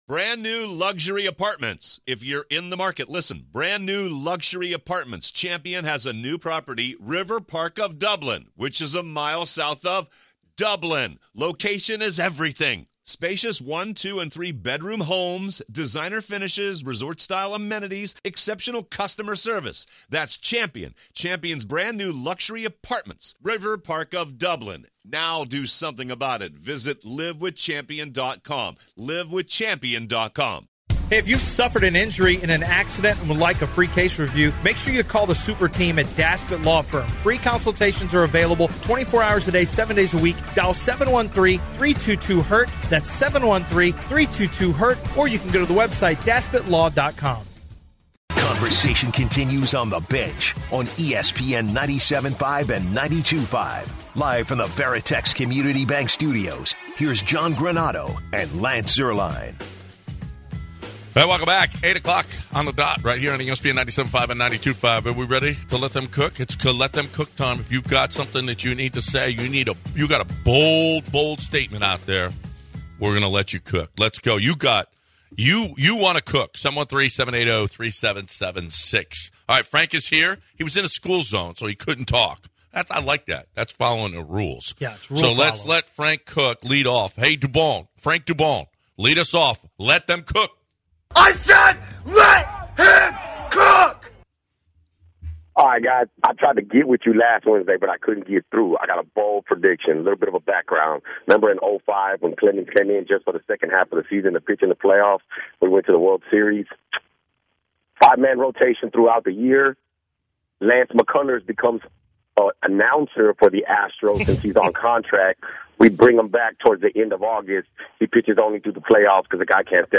giving callers an opportunity to cook and share their opinions. they first start talking the Aggie and then transition to the NBA playoffs and Clippers getting wiped out the first round against the suns...